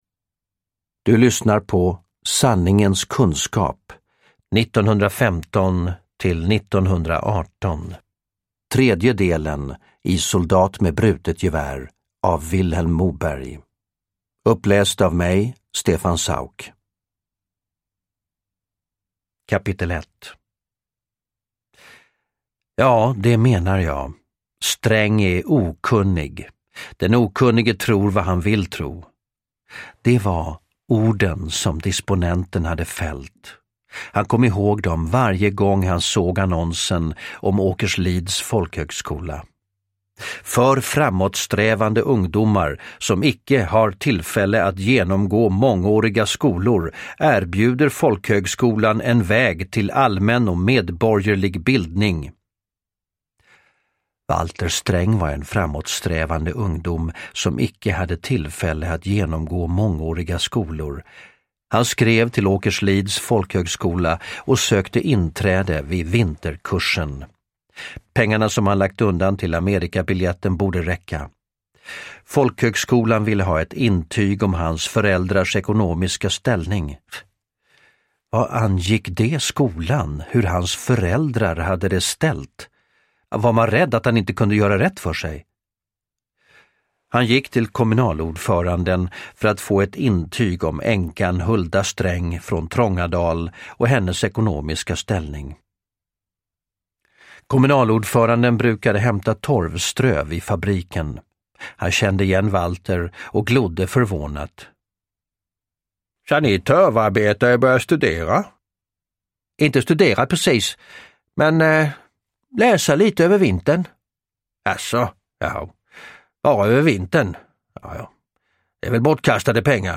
Uppläsare: Stefan Sauk